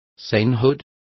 Complete with pronunciation of the translation of sainthood.